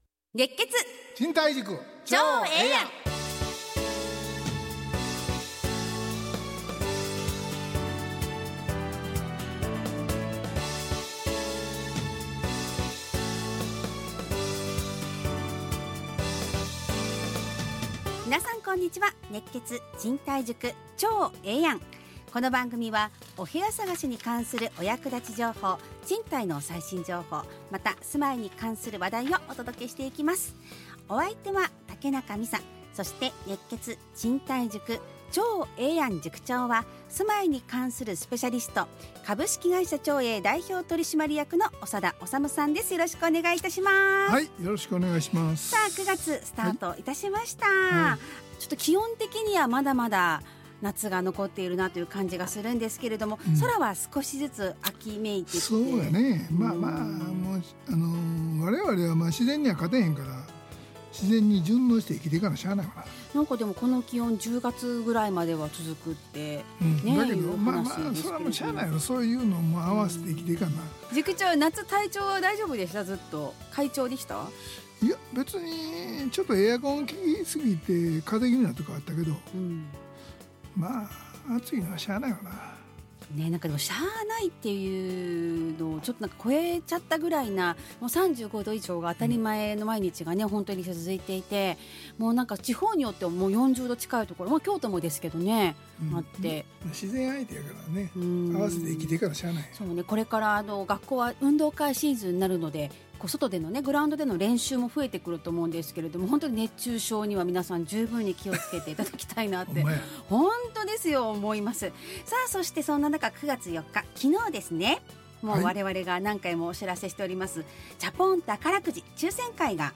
ラジオ放送 2025-09-05 熱血！賃貸塾ちょうええやん【2025.9.5放送】 オープニング：9月最初の放送、サマーチャポン抽選終わる、ベルヴィクラブとは ちょうえぇ通信：「家具家電付き賃貸」 賃貸のツボ：長栄さんの防犯への取り組みは？